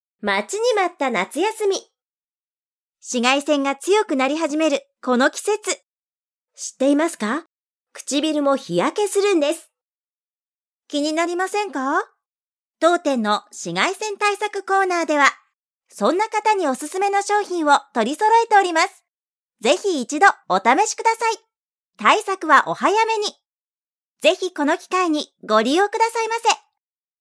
今の季節に流すのにピッタリのサンプルPOPを作ってみたので、
音声フレーズを組み合わせてみました♪